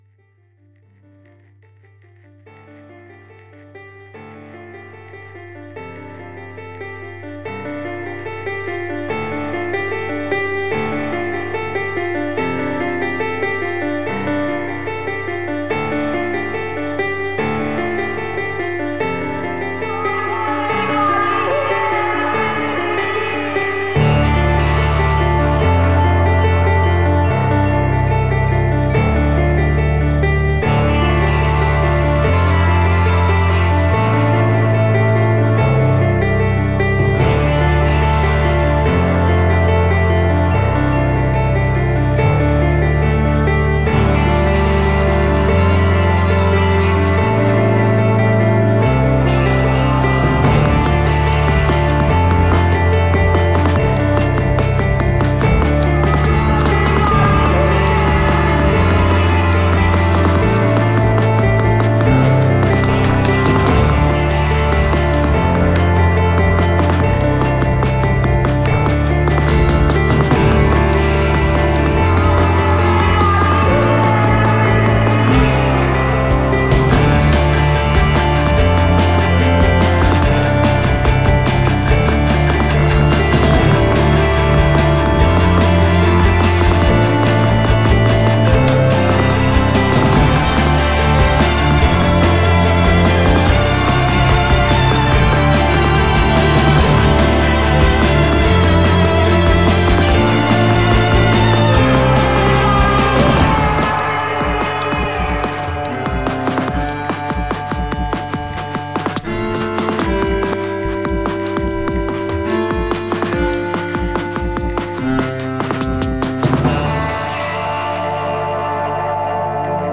Guitar, Synths, Vocals, Programming & Production
Keyboards, Programming